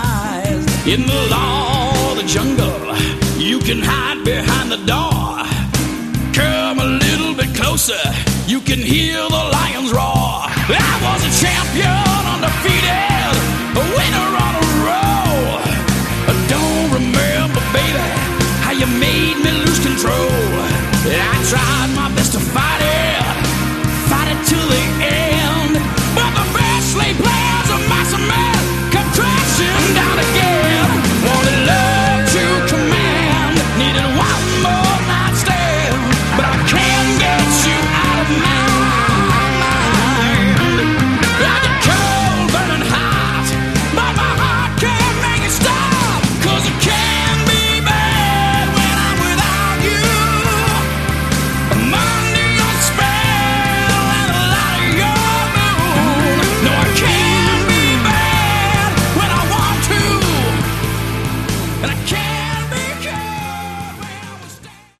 Category: Melodic Hard Rock